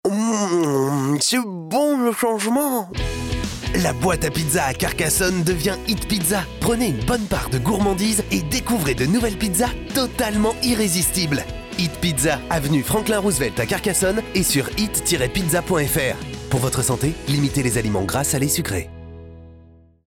Natural, Versatile, Friendly
Commercial